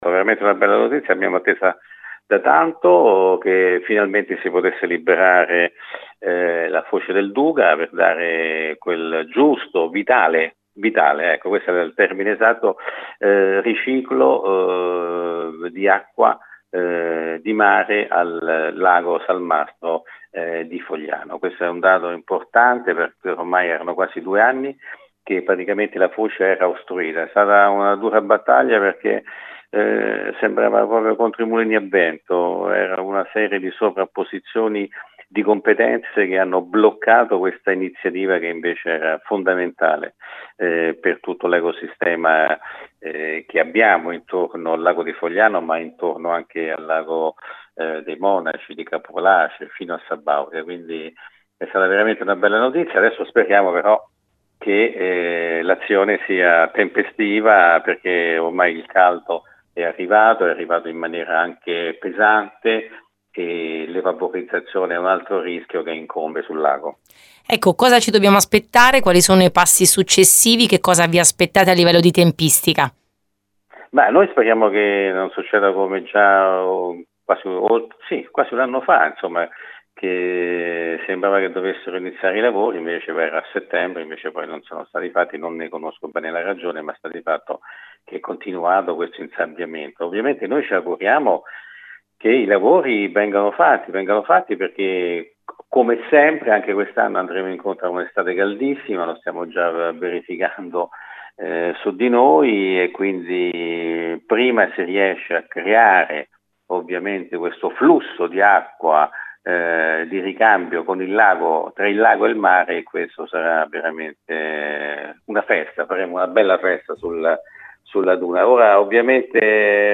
L’intervista integrale